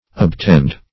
Search Result for " obtend" : The Collaborative International Dictionary of English v.0.48: Obtend \Ob*tend"\, v. t. [imp.